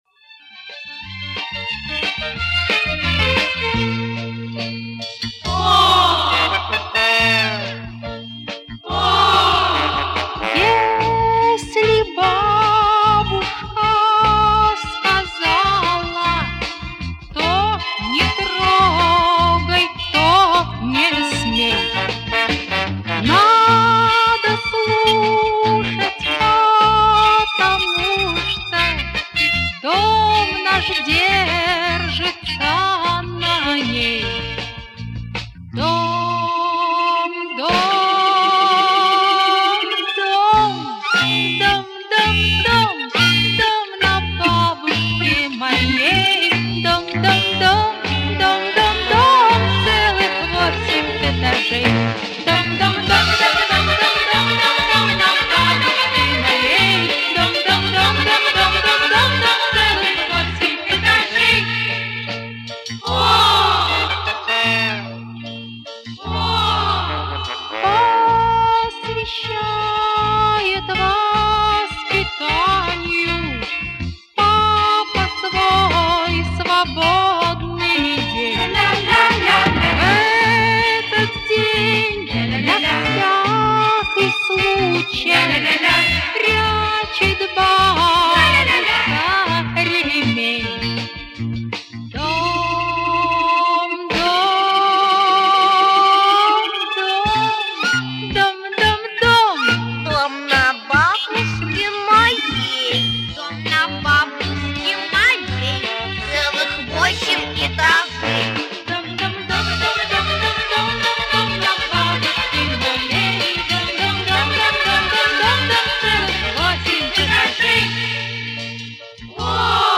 детский хор